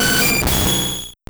Cri d'Airmure dans Pokémon Or et Argent.